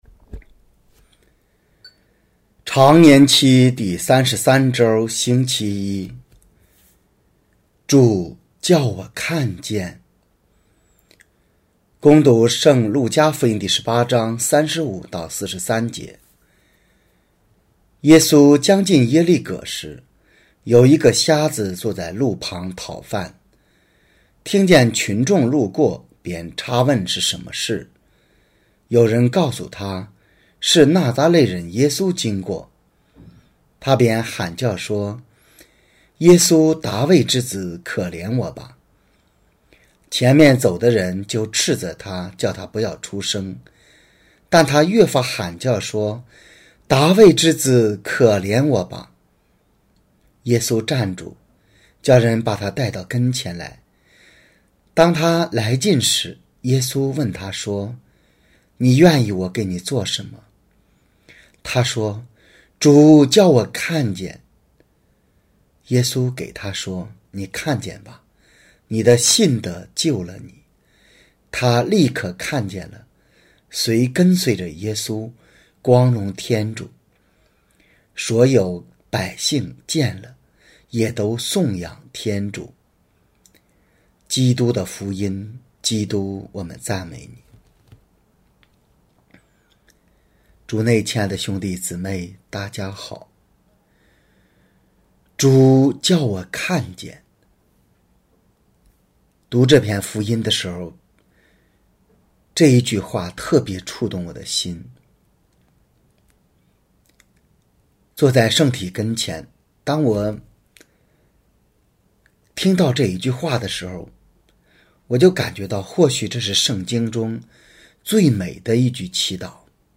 终于找到您的讲道啦！